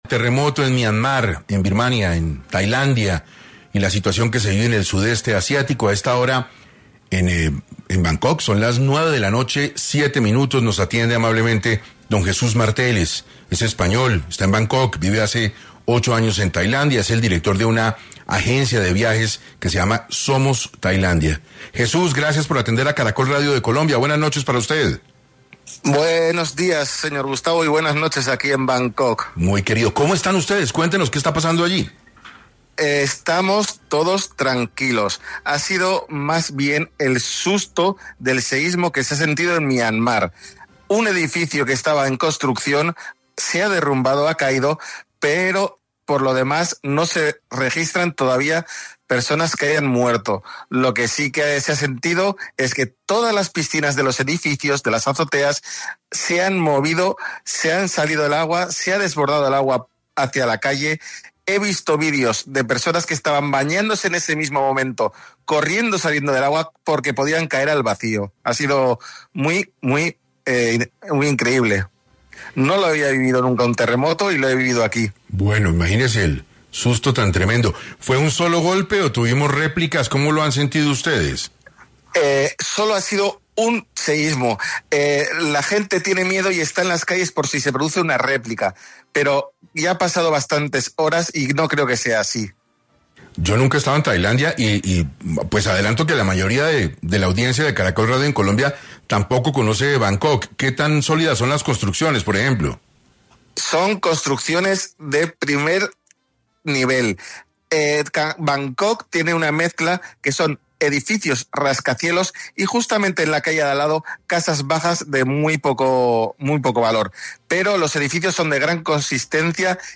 ¿Cómo se vivieron los minutos en medio del temblor en Tailandia? Testimonio desde Bangkok